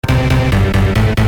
• Качество: 320, Stereo
громкие
без слов